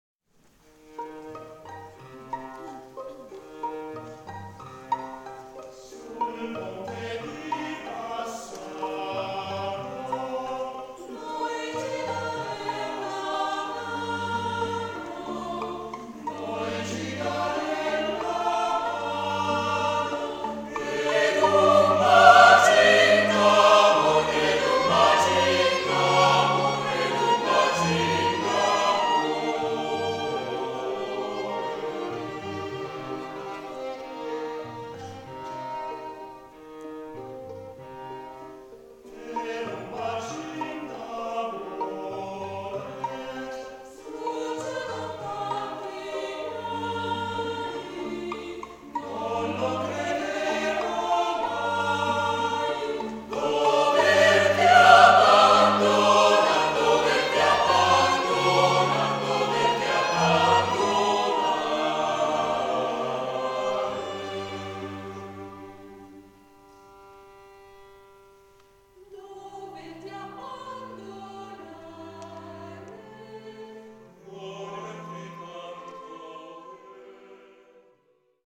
for mixed choir and string quartet - italian folksong
(live)